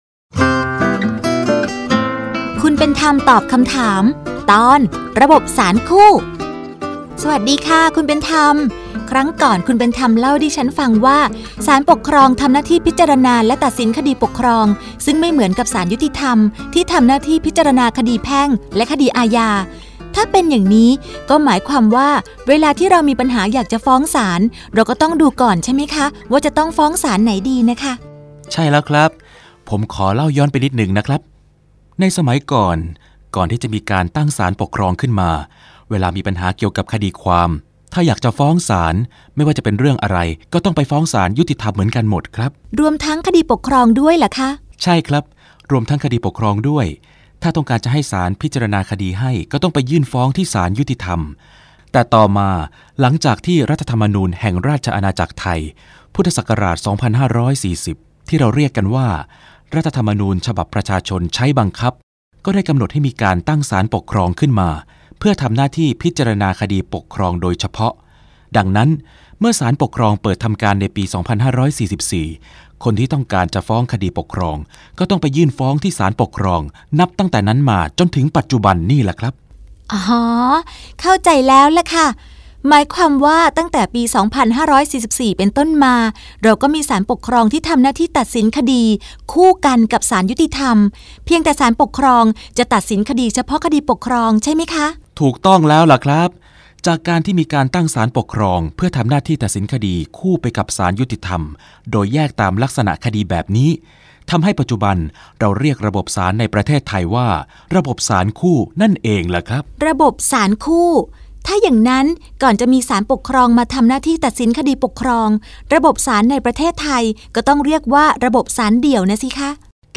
สารคดีวิทยุ ชุดคุณเป็นธรรมตอบคำถาม ตอน ระบบศาลคู่
ลักษณะของสื่อ :   คลิปเสียง, คลิปการเรียนรู้